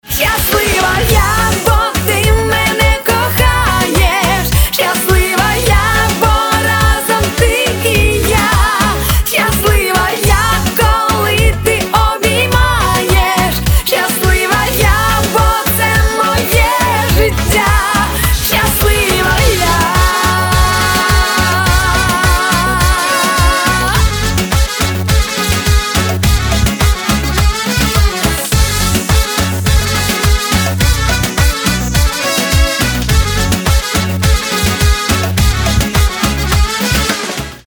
• Качество: 320, Stereo
поп
громкие
красивые